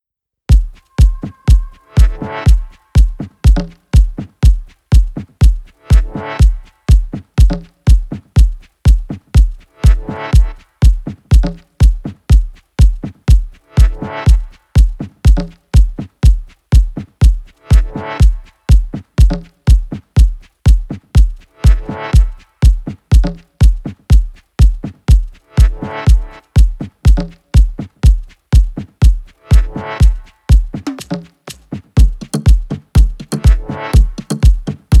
Жанр: Танцевальные / Техно